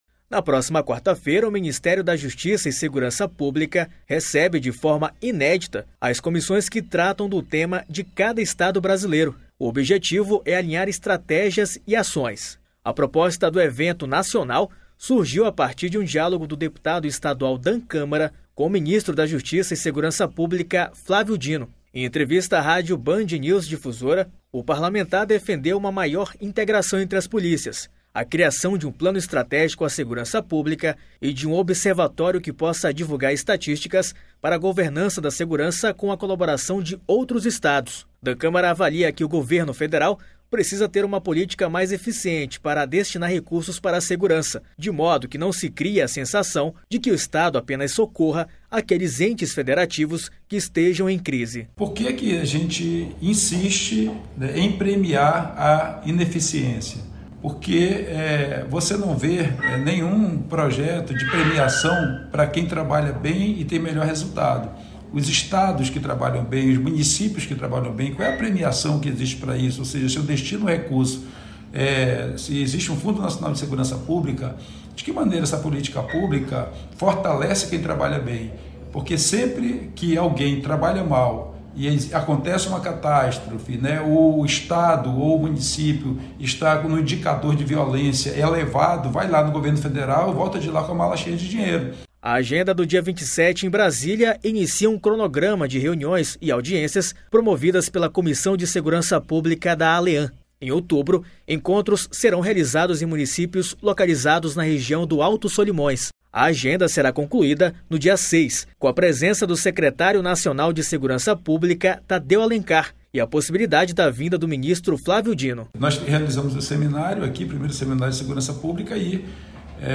Em entrevista a rádio BandNews Difusora FM, o parlamentar defendeu uma maior integração entre as polícias, a criação de plano estratégico à segurança pública e de um Observatório que possa divulgar estatísticas para a governança da segurança com a colaboração de outros estados